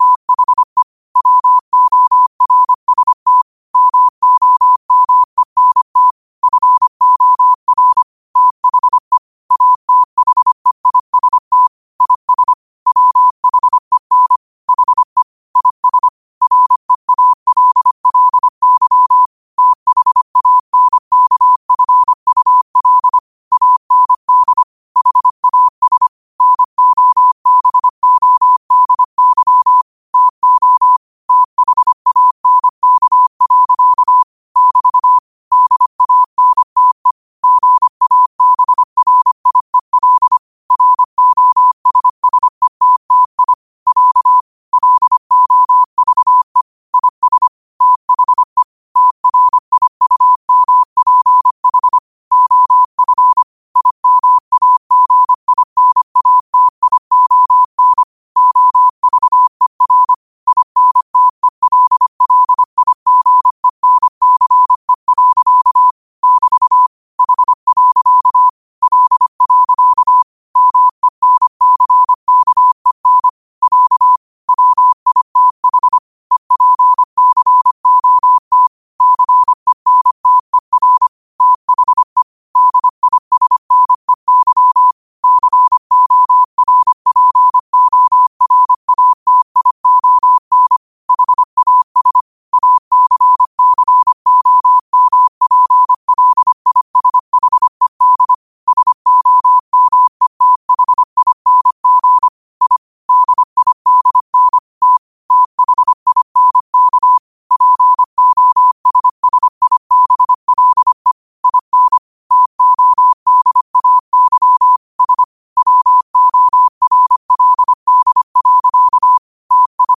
25 WPM morse code quotes for Fri, 15 Aug 2025 by QOTD at 25 WPM
Quotes for Fri, 15 Aug 2025 in Morse Code at 25 words per minute.
Play Rate Listened List Bookmark Get this podcast via API From The Podcast Podcasts of famous quotes in morse code.